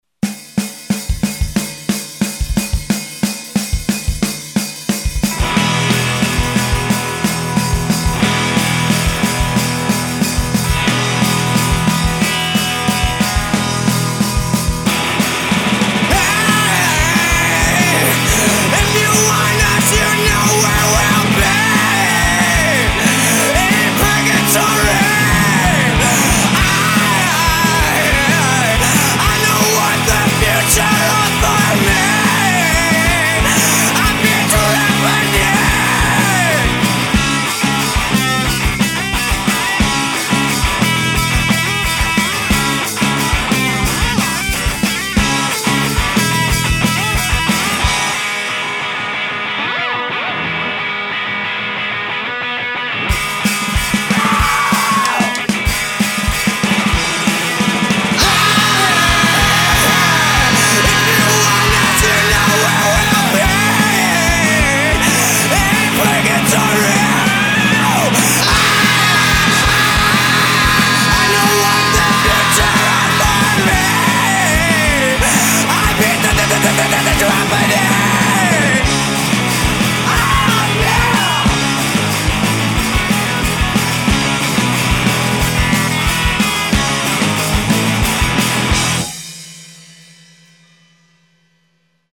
the saviors of jangly noodly punk rock.